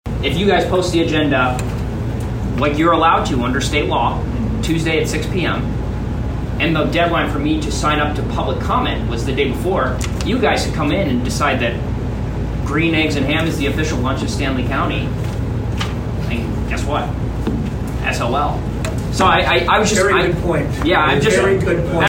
At last night’s (Sept. 14, 2022) meeting